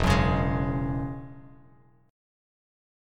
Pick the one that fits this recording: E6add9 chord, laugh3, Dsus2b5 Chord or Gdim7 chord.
E6add9 chord